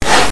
opendoor.mp3